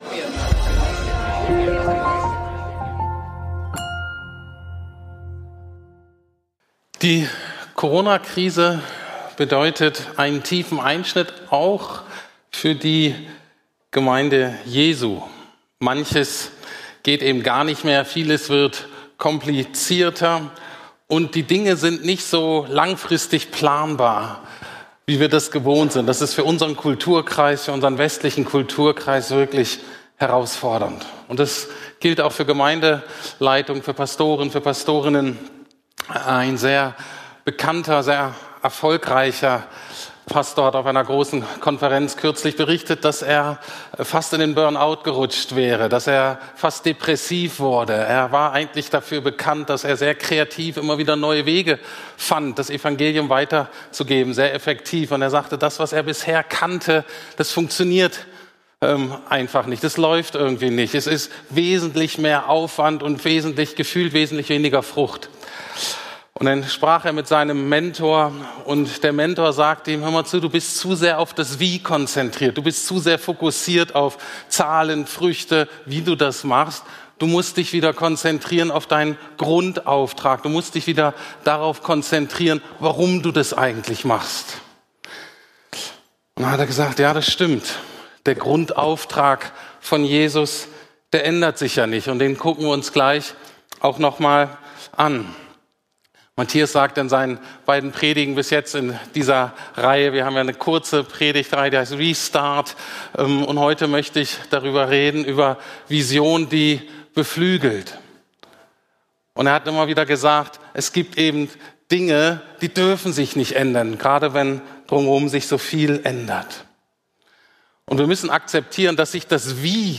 Vision, die beflügelt ~ Predigten der LUKAS GEMEINDE Podcast